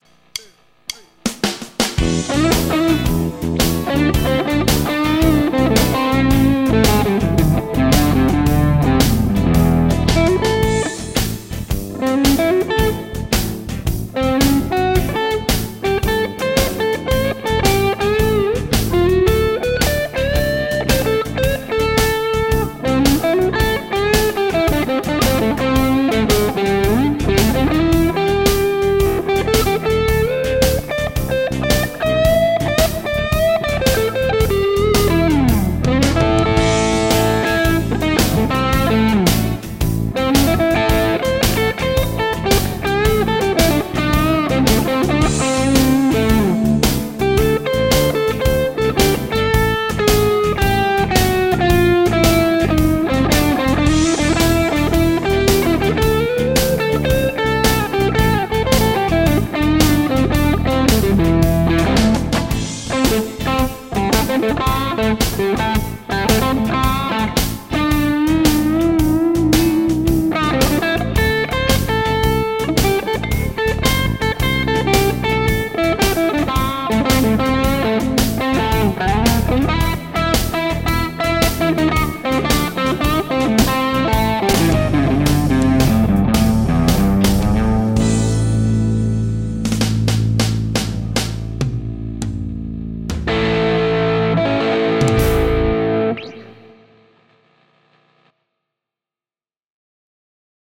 Bendit, taimi ja tekninen toteutus.
mukavan rohea soundi pikkaisen kulmikkailla otteilla